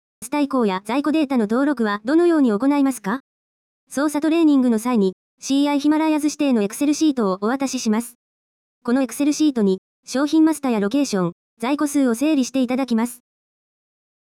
そのような方に、まずは耳からお試しいただけるよう、【ci.Himalayas/WMS よくあるご質問】を実際のシステム音声でサウンド化しました。
それでは、CCSの音声システムで実際に稼動しているシステム音声による【ci.Himalayas/WMS よくあるご質問】をお試しください。